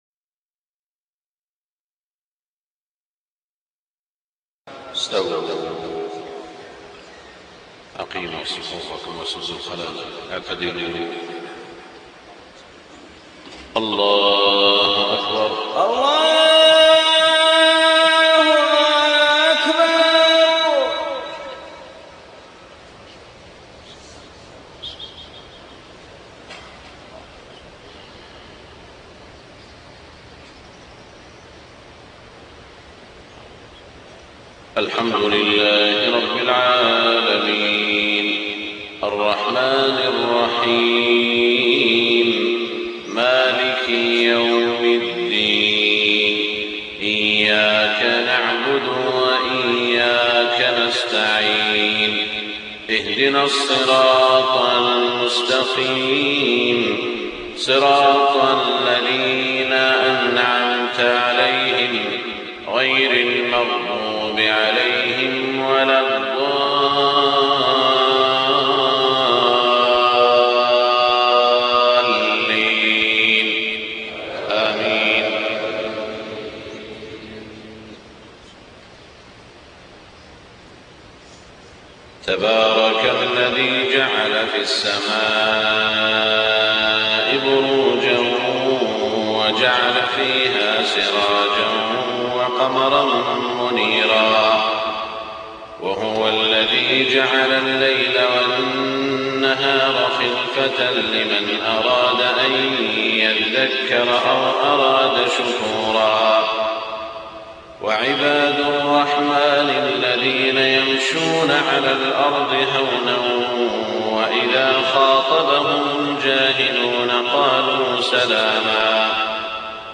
صلاة الفجر 23 شعبان 1428هـ من سورتي الفرقان و الشعراء > 1428 🕋 > الفروض - تلاوات الحرمين